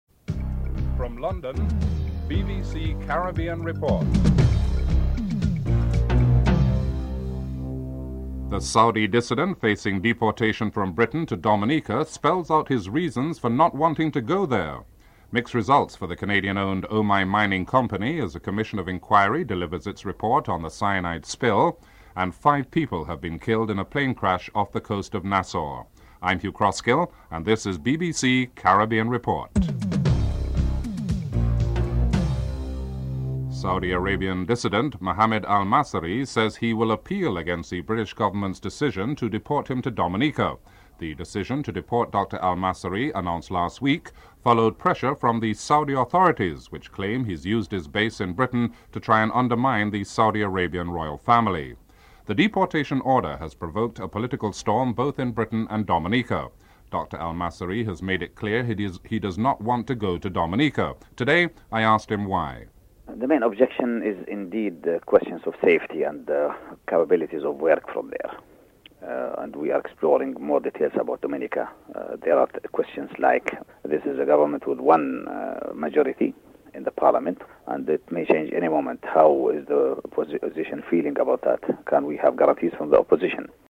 Mohammed Al-Massari, Saudi dissident is interviewed (00:32-05:46)
Rosie Douglas is interviewed (09:56-11:51)